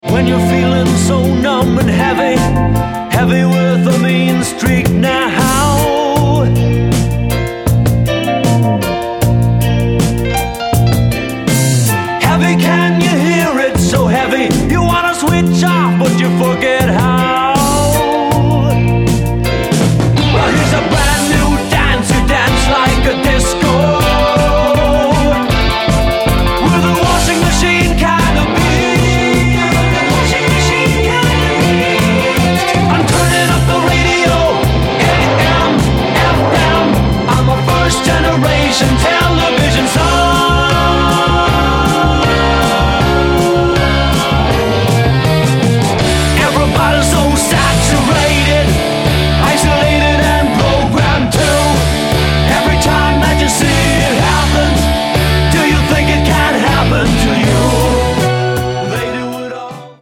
Lead Vocal, Keyboards & Percussion.
Guitars & Vocal.